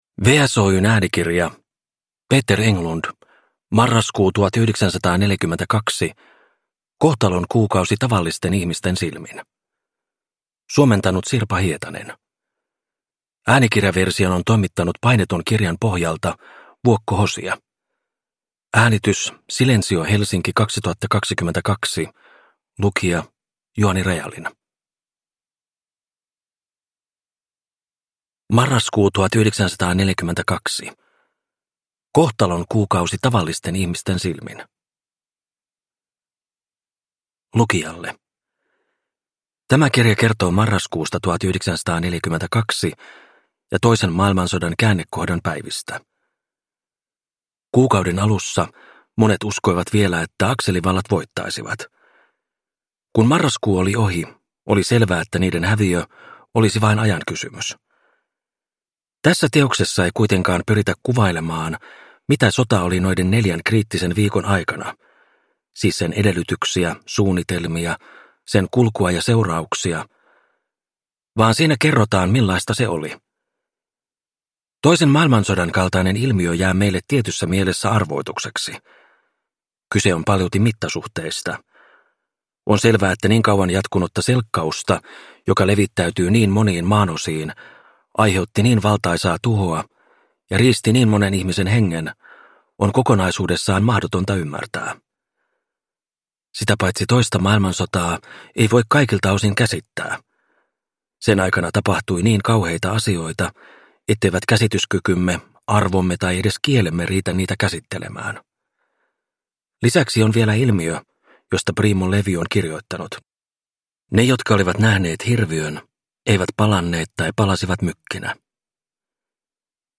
Marraskuu 1942 – Ljudbok – Laddas ner